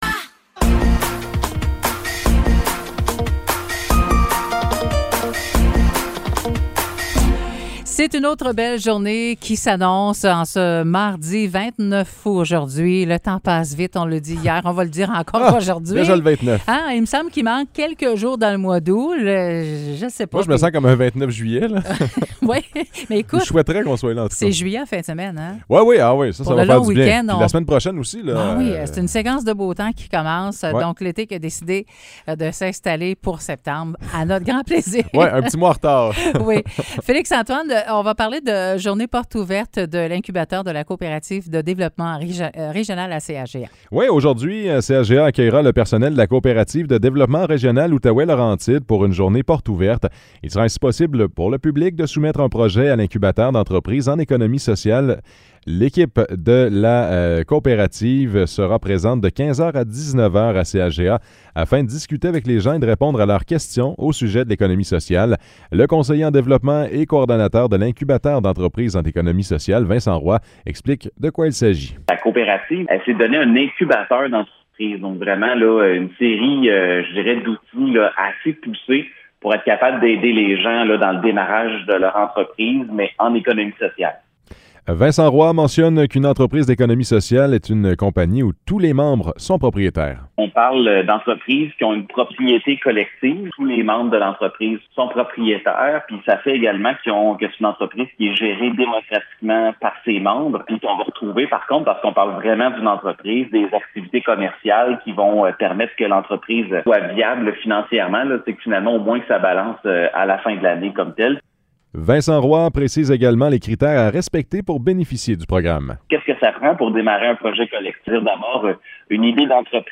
Nouvelles locales - 29 août 2023 - 9 h